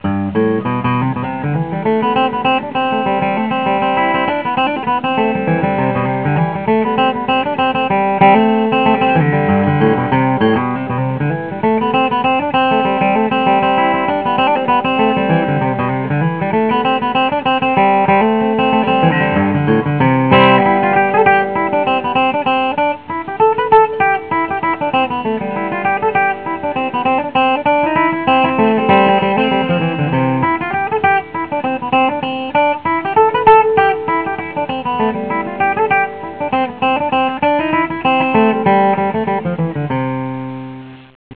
I recorded the songs right at my desk, on my Macintosh.
Fiddle and Banjo Tunes: